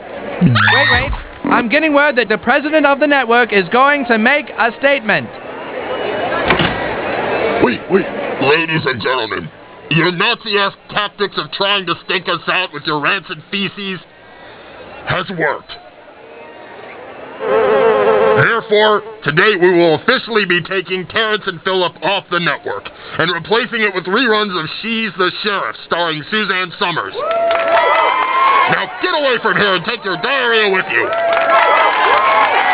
President of the network makes a statement.